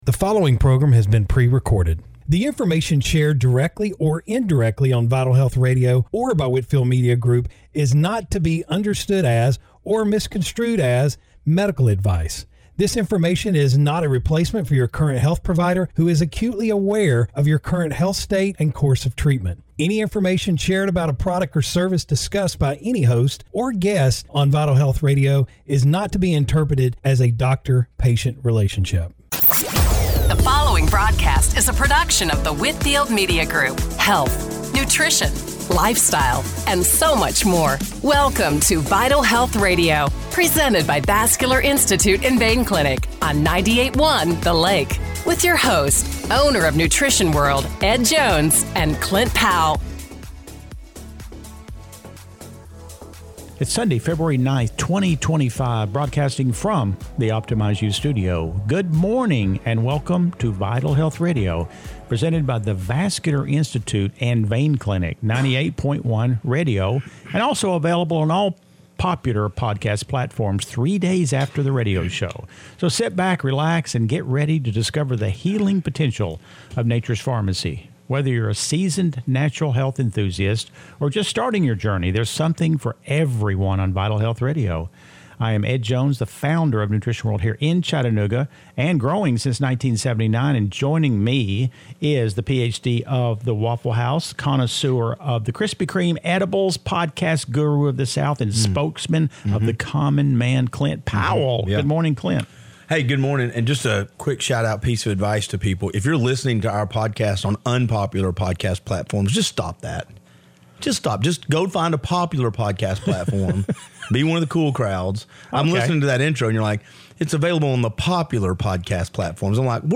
Radio Show – February 9, 2025 - Vital Health Radio